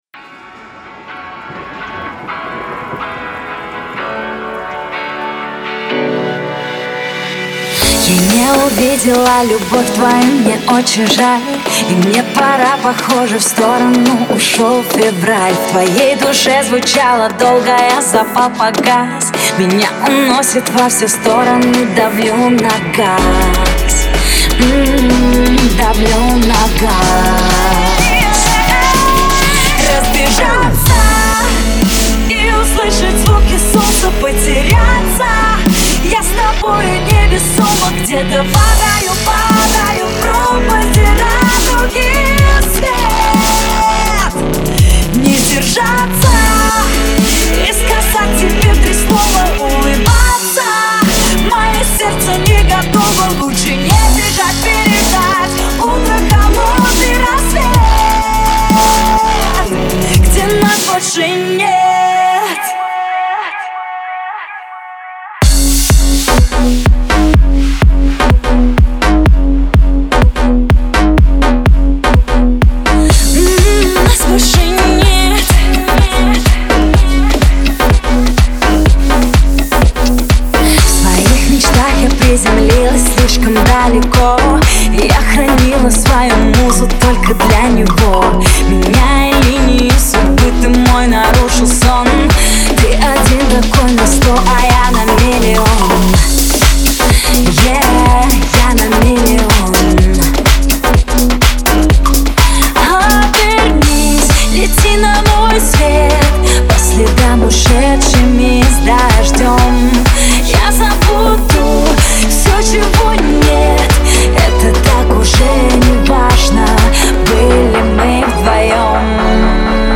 Жанр:Русские новинки / Клубные новинки